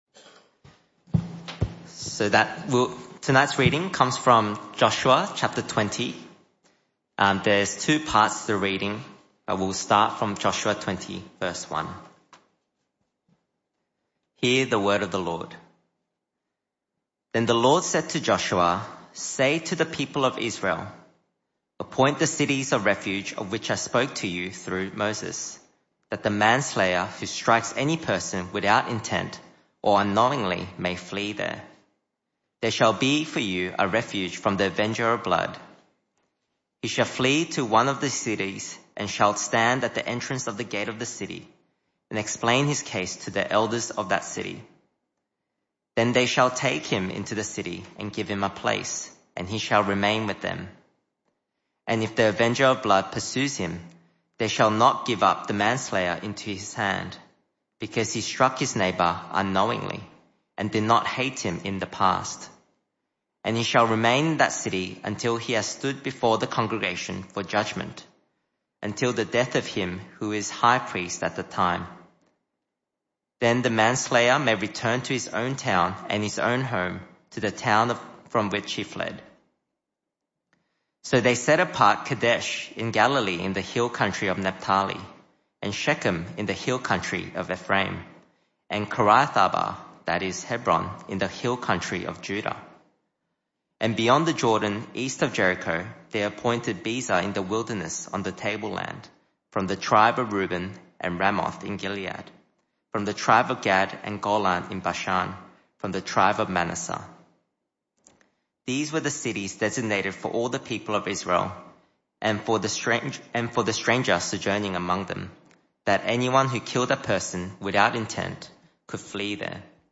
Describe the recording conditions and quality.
This talk was part of the PM Service series entitled The People Of God’s Promise. Service Type: Evening Service